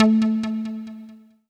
synTTE55013shortsyn-A.wav